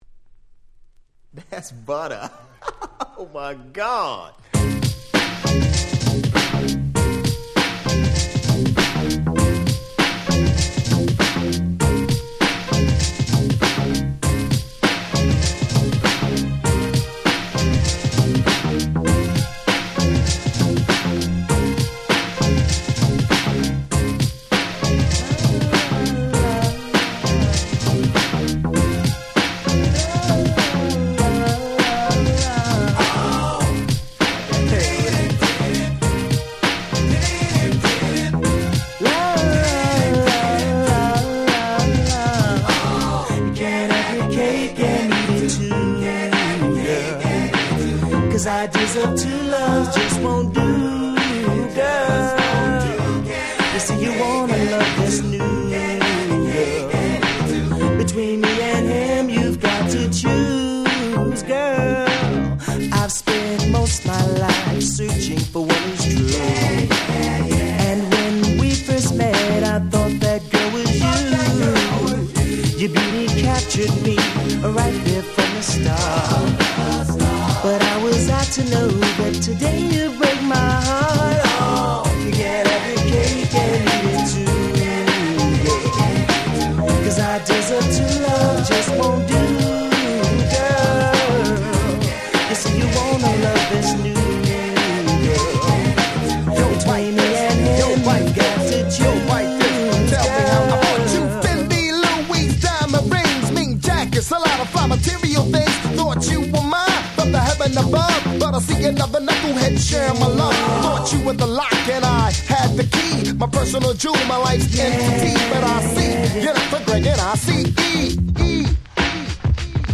MellowなBeatに彼らの掛け合いが最高です！
ナイスアンドスムース 90's Boom Bap ブーンバップ